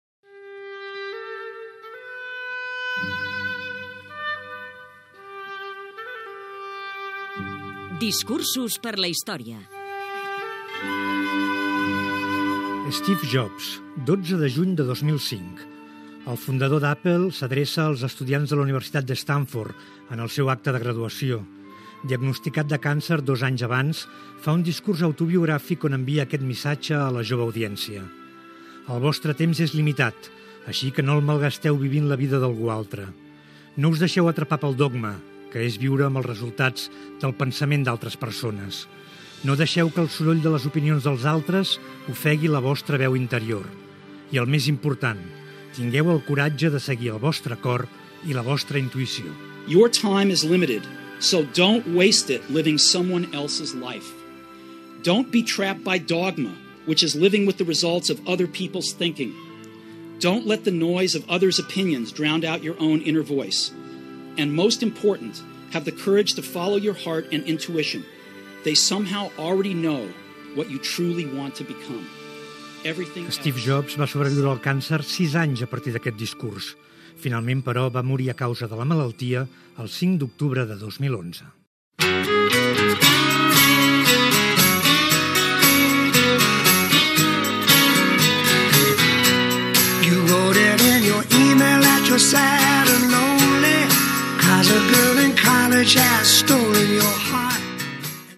"Discursos per a la història": discurs de Steve Jobs, el fundador d'Apple, als estudiants de la Universitat de Stanford en el seu acte de graduació, pronunciat el 12 de juny de 2005
Info-entreteniment